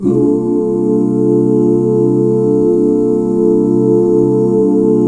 CSUS13 OOO-L.wav